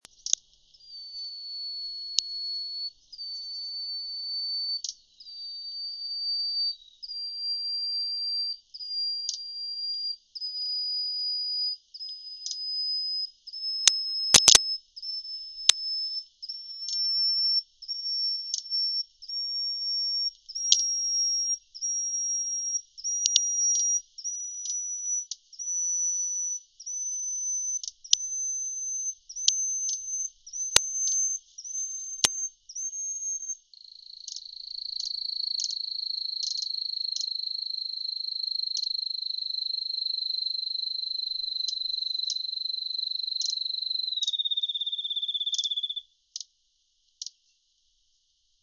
30-6塔塔加2012mar26深山鶯song2g.mp3
黃腹樹鶯 Cettia acanthizoides concolor
南投縣 信義鄉 塔塔加
錄音環境 森林
鳥叫